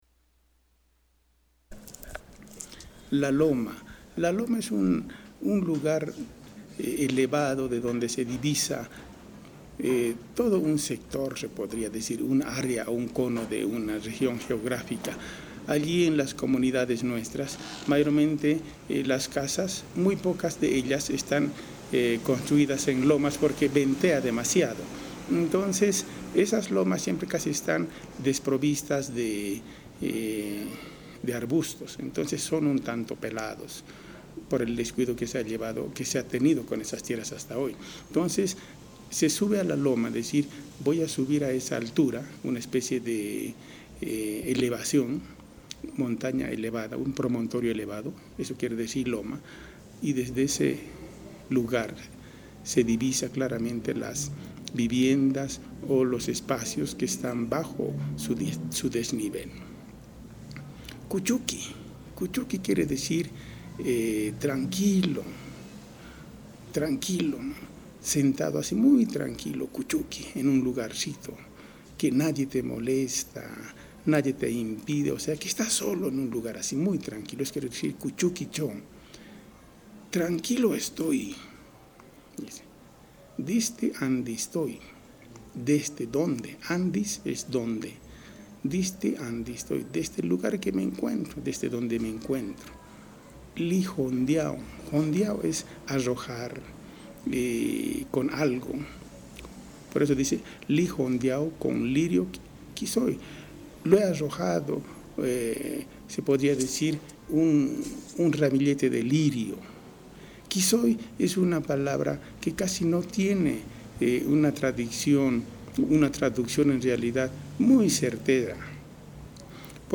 explica el significado